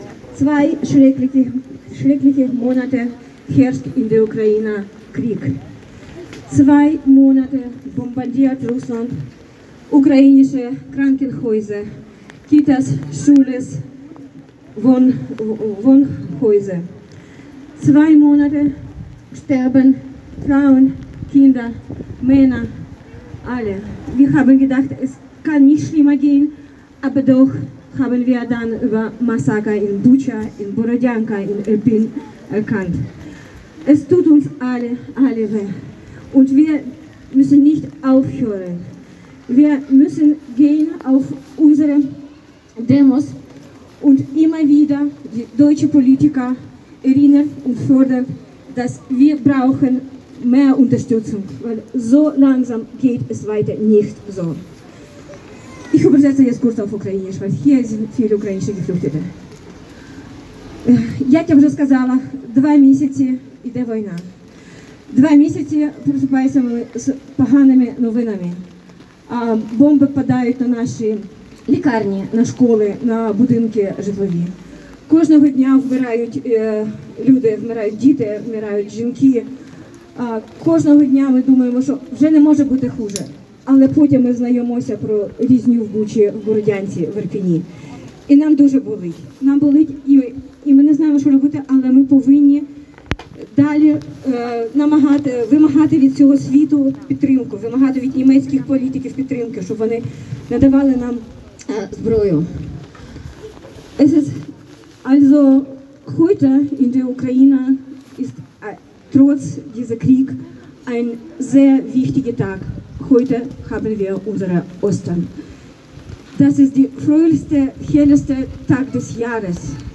Solidaritätskundgebung für die Ukraine
Heute (24.04.2022) kamen auf dem Platz der alten Synagoge in Freiburg circa hundert Menschen in strömenden Regen zusammen um sich solidarisch mit der Ukraine zu zeigen.
Reden in voller Länge
Deutsch-Ukrainische Gesellschaft Freiburg e.V. (mit ukrainischer Übersetzung)